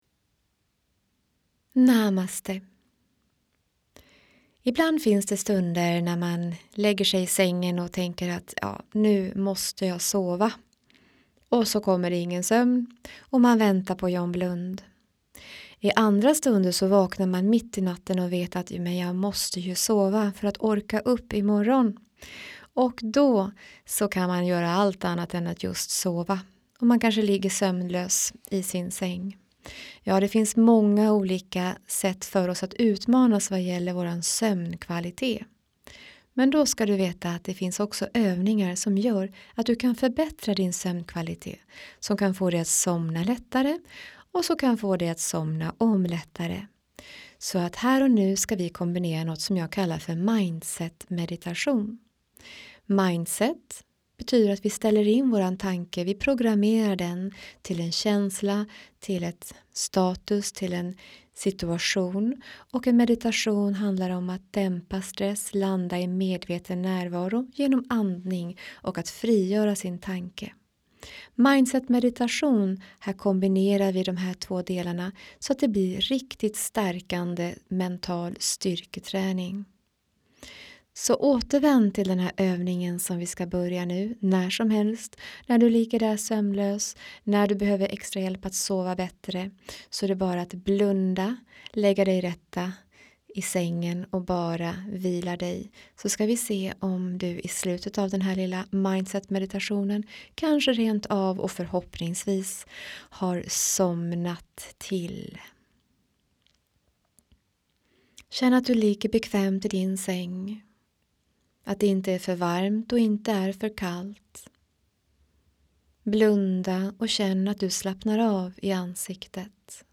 En wellness meditation i natten när du behöver lugn och ro.
En wellness röst i natten helt enkelt med lite andningsövningar och meditation 😉 Det är bara att trycka på play och lyssna med…